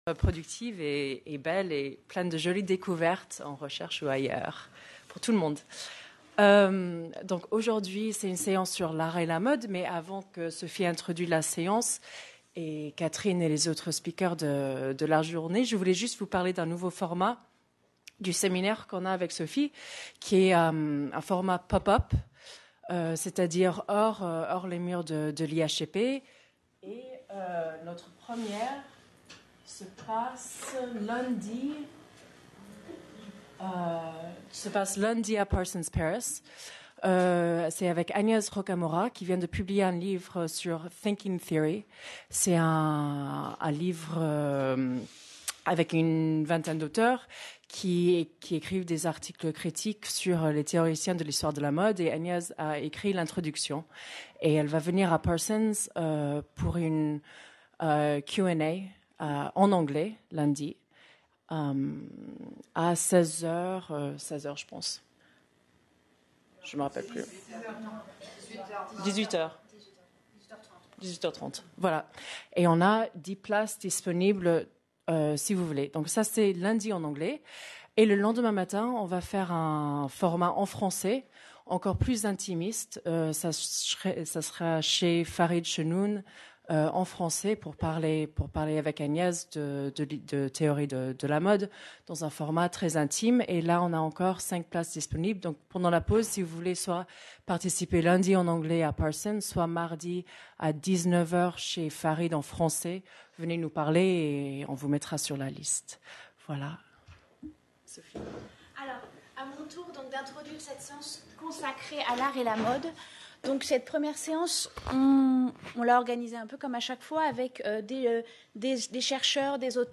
Séminaire "L'art de la mode"